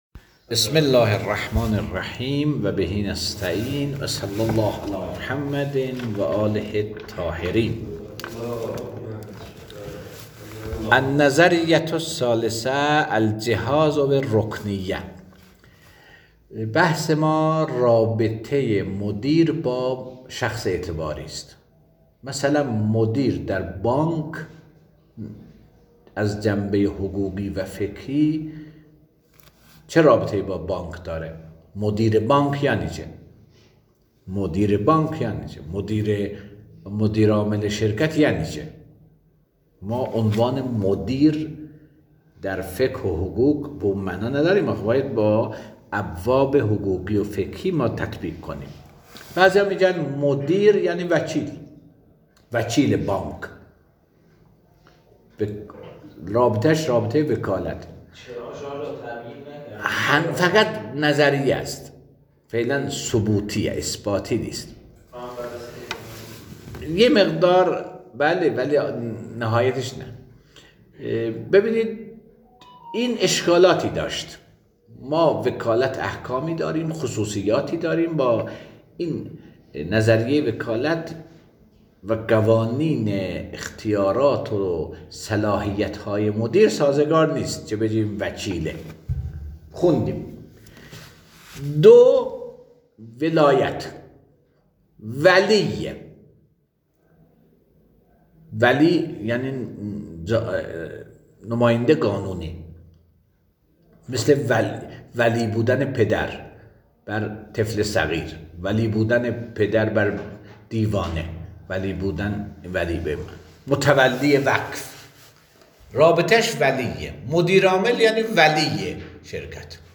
درس الشخص الاعتباری (ج۲) جلسه ۲۱